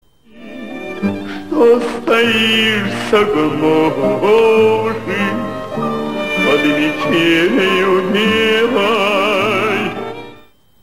Русский романс (500)
Правильный ответ: Николай Алексеевич Сличенко